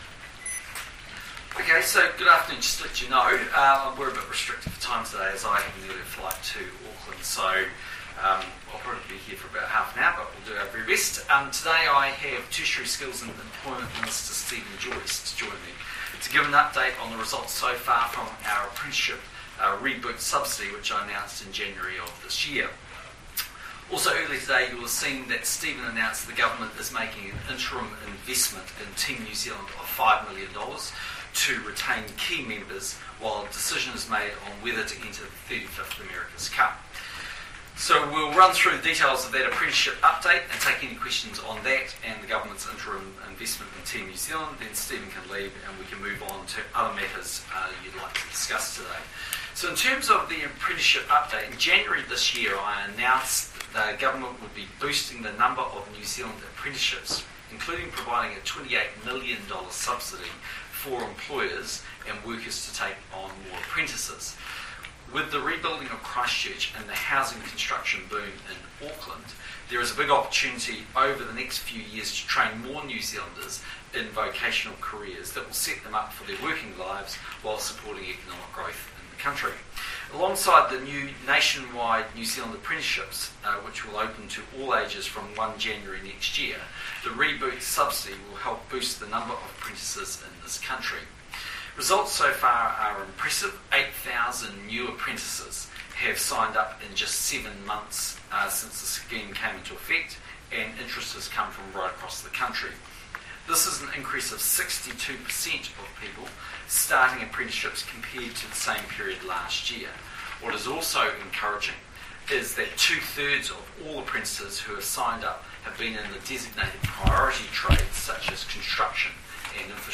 PM Post-Cabinet Press Conference - October 21, 2013 | Scoop News
The Prime Minister held a press conference today with Steven Joyce to discuss apprenticeship reboot subsidies, and the government's interim investment of $5m in Team NZ.